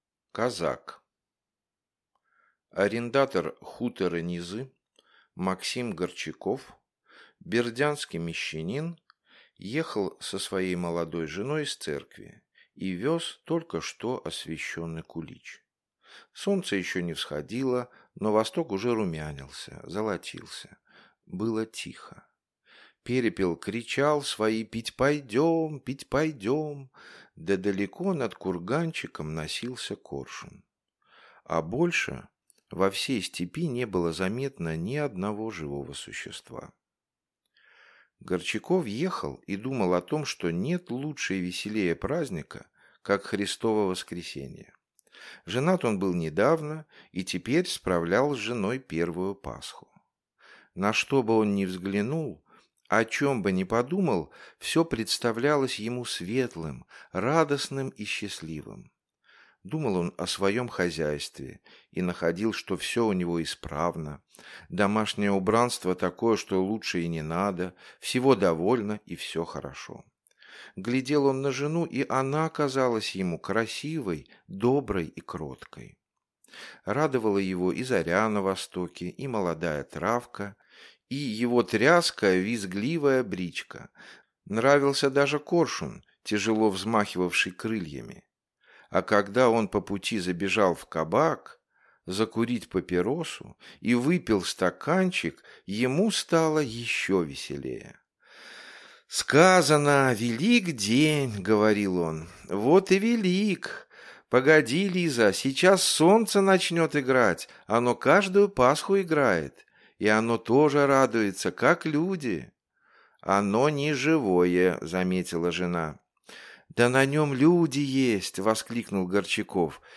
Аудиокнига Казак